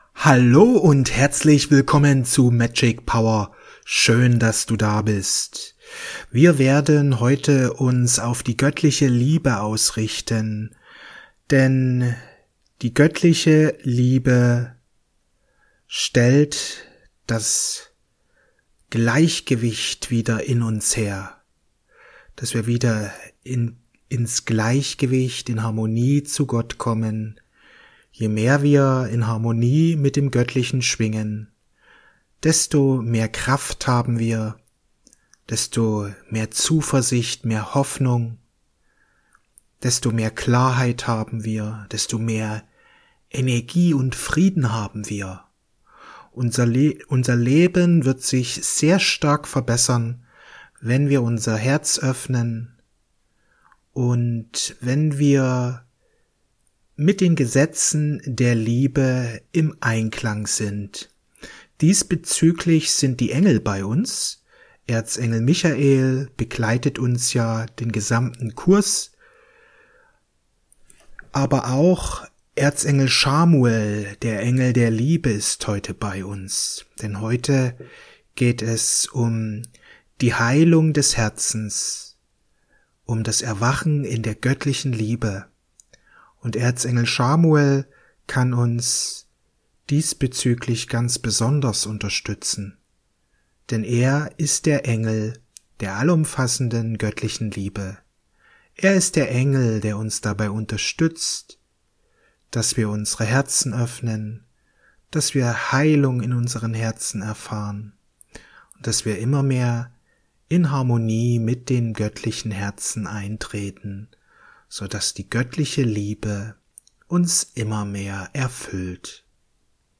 Meditation mit Erzengel Chamuel